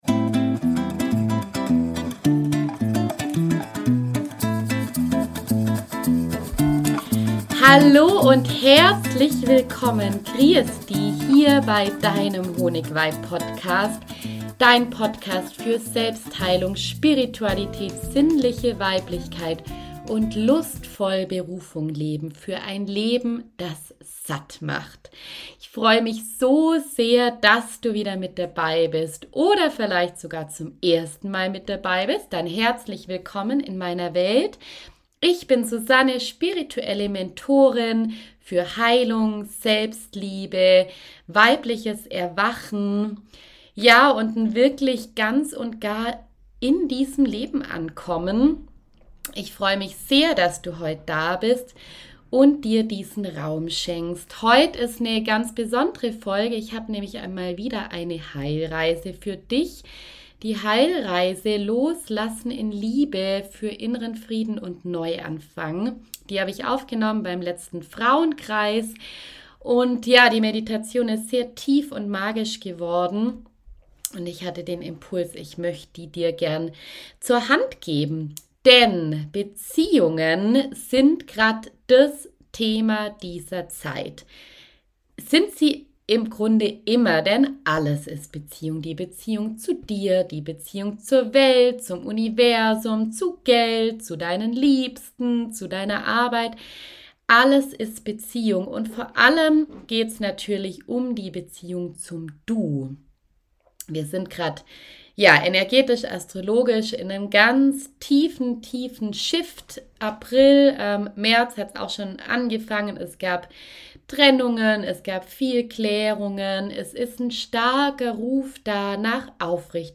Ich lade dich ein, tief in dein Unterbewusstsein hinein zu tauchen und einem Menschen zu begegnen- oder auch einer vergangenen Version von dir selbst, mit dem es noch etwas zu klären gibt. In dieser geführten Reise lade ich dich ein, auf energetischer Ebene Abschied zu nehmen oder auf eine neue Ebene zu wechseln– in Liebe, in Würde und in Verbindung mit dir selbst.